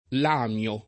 vai all'elenco alfabetico delle voci ingrandisci il carattere 100% rimpicciolisci il carattere stampa invia tramite posta elettronica codividi su Facebook lamio [ l # m L o ] s. m. (bot.); pl. lami (raro, alla lat., lamii )